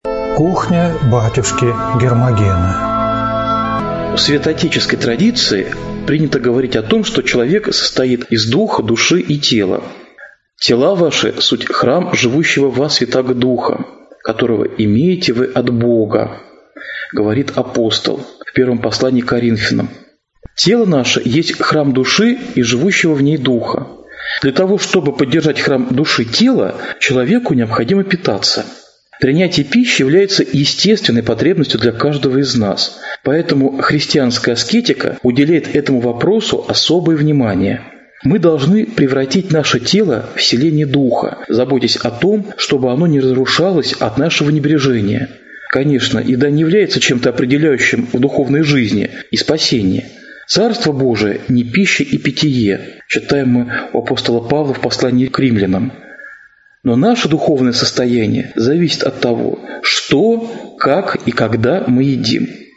Аудиокнига Кухня батюшки Гермогена | Библиотека аудиокниг